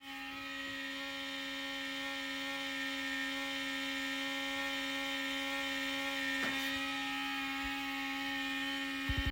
Im Anhang das Geräusch des Lüfters auf voller Drehzahl.
Windows 10 HP Ryzen 7 AMD CPU Auslastung nur wenige Prozent Im BIOS habe ich die Option "Fan Power" auf disabled Gehäuse wurde aufgemacht und gesäubert Anhänge Lüfter Geräusch HP Ryzen 7.m4a Lüfter Geräusch HP Ryzen 7.m4a 90,3 KB